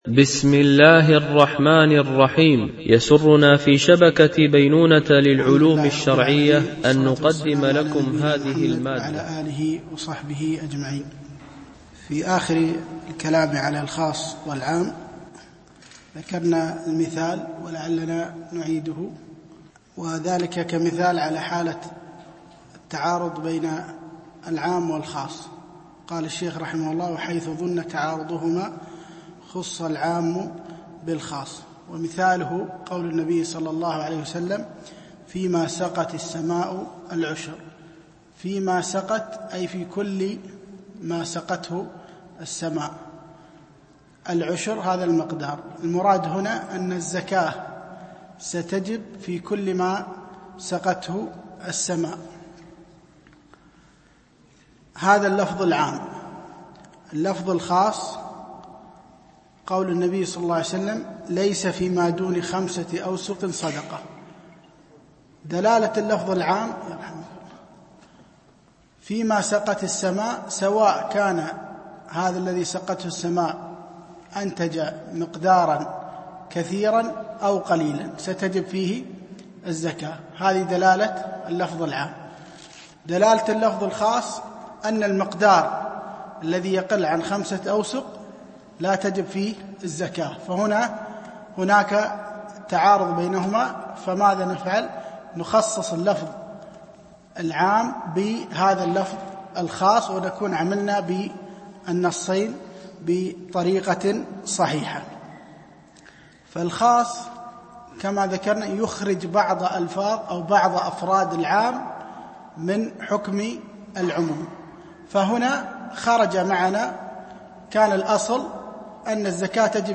شرح رسالة لطيفة جامعة في أصول الفقه المهمة - الدرس 4 ( من قوله: ومنها مطلق عن القيود)